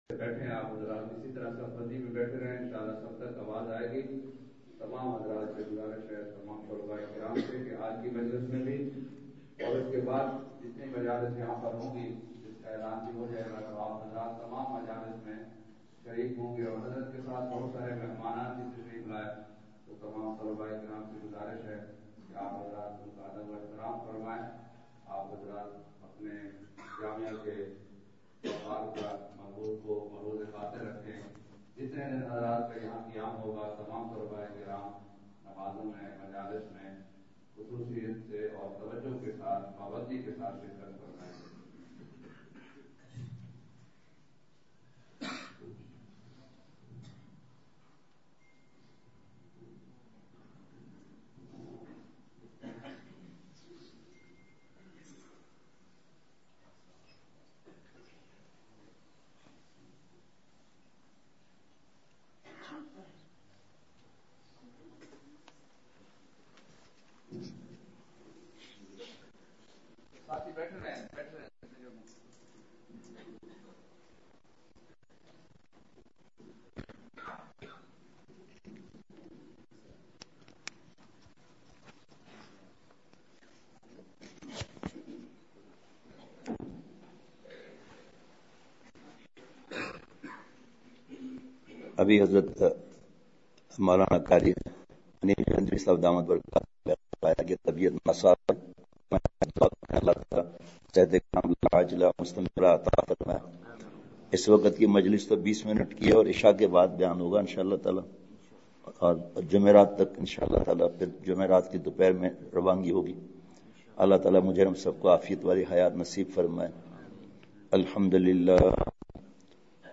*بمقام: جامعہ خیرالمدارس ملتان*
فجر نماز بعد مختصر بیان ہوا۔۔